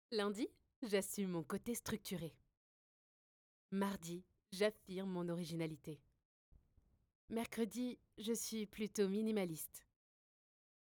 Une voix qui ne triche pas, et une présence simple, chaleureuse et pleine de vie.
13 - 45 ans - Mezzo-soprano